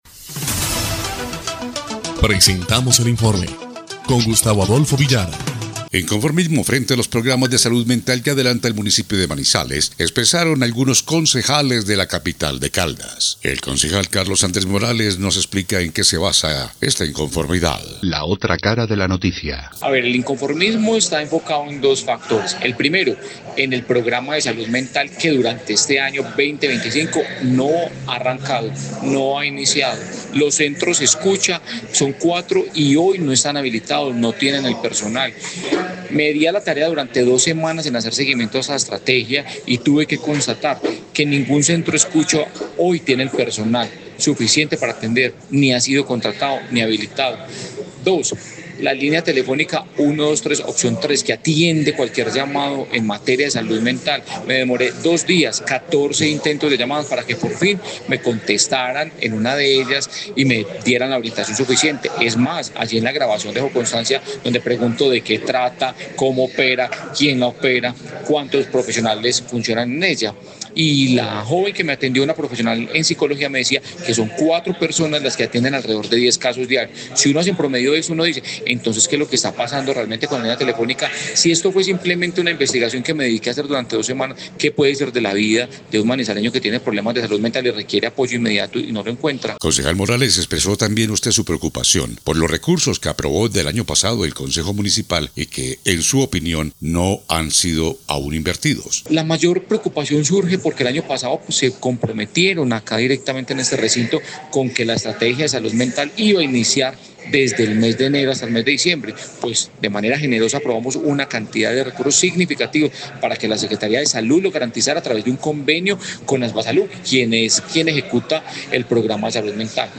INFORME ESPECIAL – LA OTRA CARA DE LA NOTICIA
Concejal de Manizales Carlos Andrés Morales